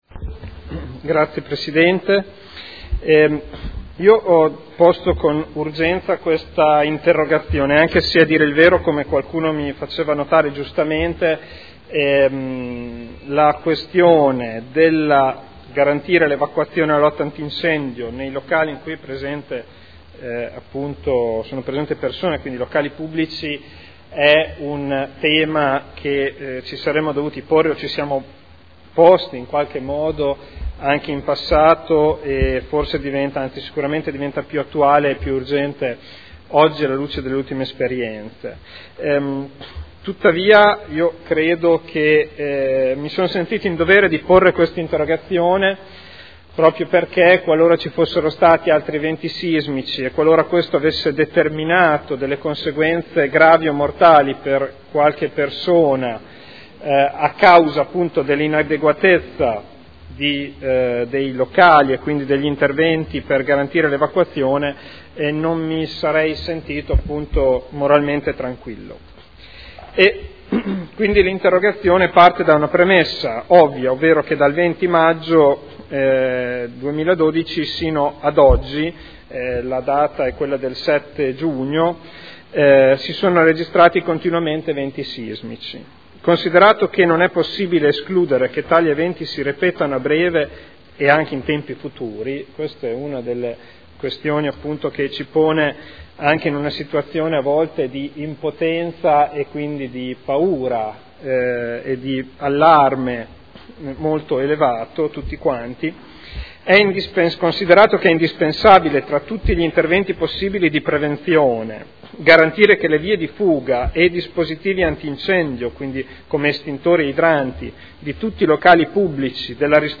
Federico Ricci — Sito Audio Consiglio Comunale